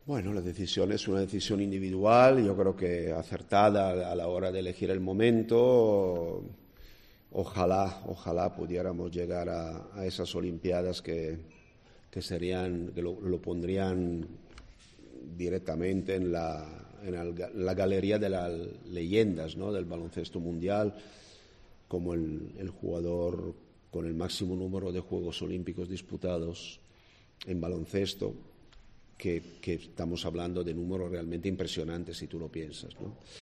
En la rueda de prensa previa a la conferencia, Sergio Scariolo ha considerado "acertada" la decisión de Rudy de retirarse a final de temporada.